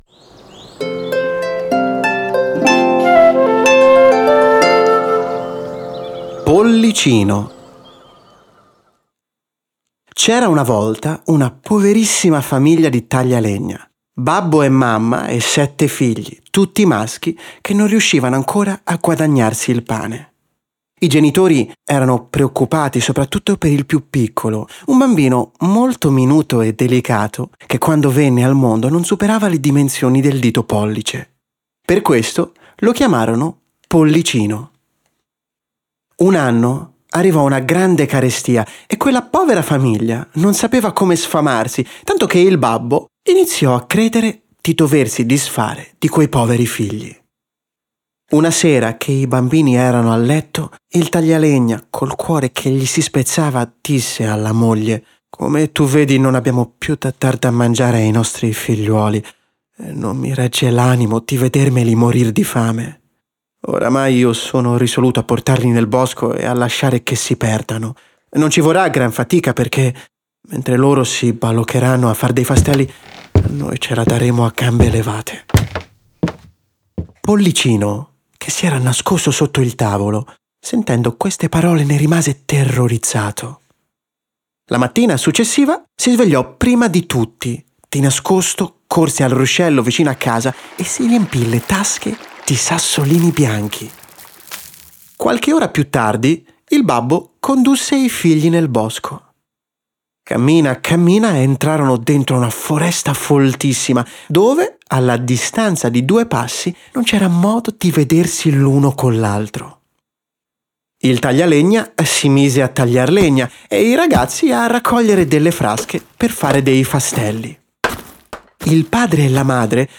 In ogni episodio, Vittorio De Sica, seduto davanti a un camino acceso trasportava grandi e piccini in un mondo incantato, raccontando con la sua voce calda e avvolgente fiabe di tutti i tempi e di tutti i Paesi.